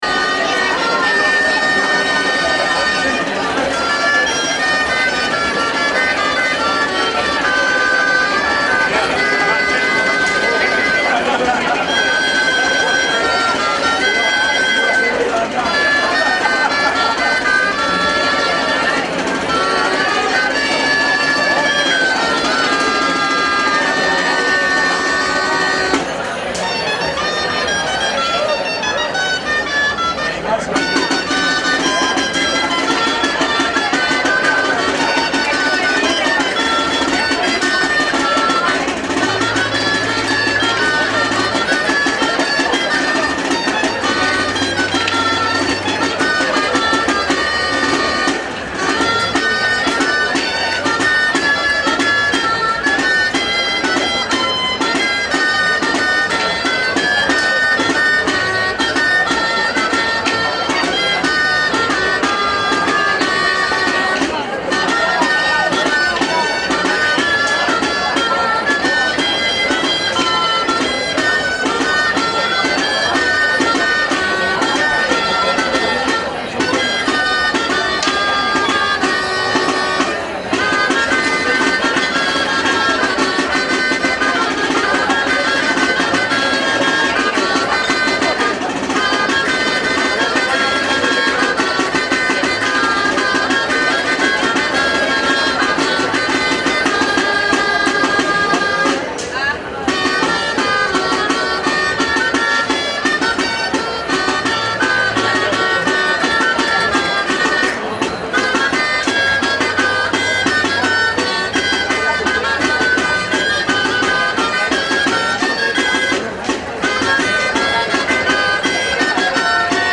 DANZAS EN HONOR DE LOS SANTOS PATRONOS DE MARCILLA | Atlas de las danzas de Navarra
Martzilla-2018-Danza de arcos.mp3